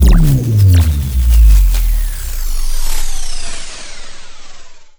sci-fi_electric_pulse_power_down_02.wav